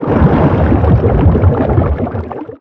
File:Sfx creature shadowleviathan swimfast 01.ogg - Subnautica Wiki
Sfx_creature_shadowleviathan_swimfast_01.ogg